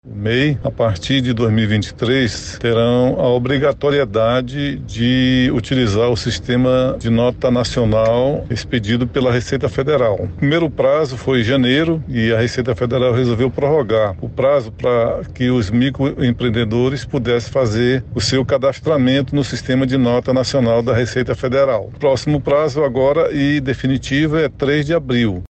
O subsecretário de Receita da Secretaria Municipal de Finanças e Tecnologia da Informação – Semef, Armínio Pontes, ressalta que o Comitê Gestor do Simples Nacional estipulou um prazo para que a categoria pudesse de adequar à mudança.